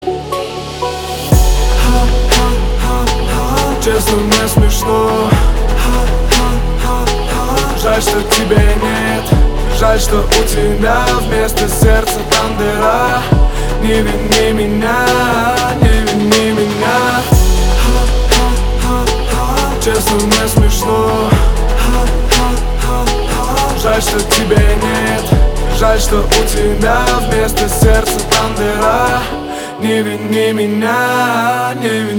• Качество: 320, Stereo
лирика
русский рэп